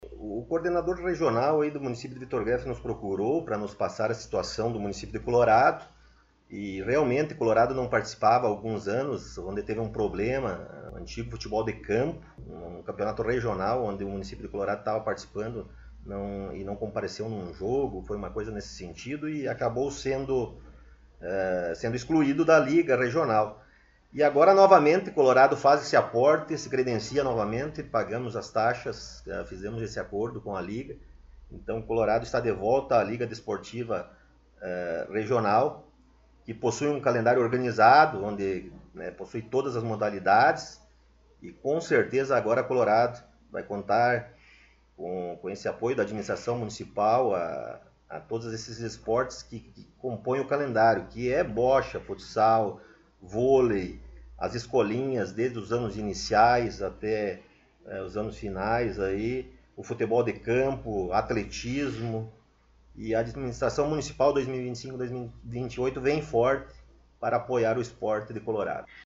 O Colorado em Foco esteve entrevistando na última sexta-feira ( 17 ), no gabinete da Prefeitura Municipal o prefeito Rodrigo Sartori.